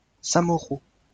Samoreau (French pronunciation: [samɔʁo]